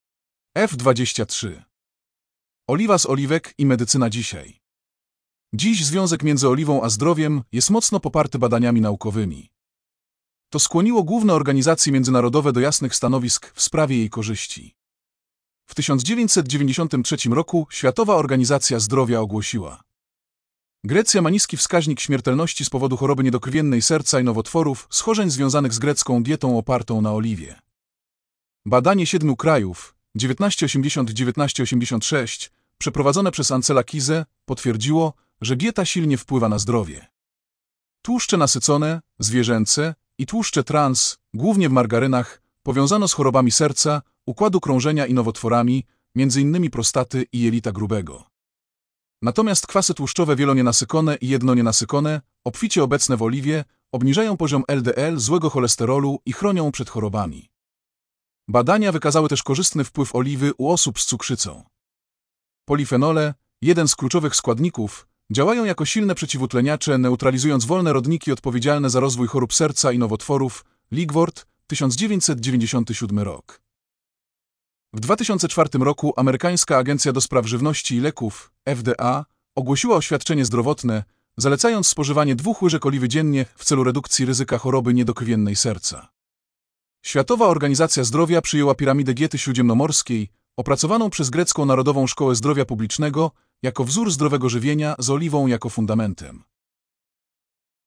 Wycieczka z przewodnikiem audio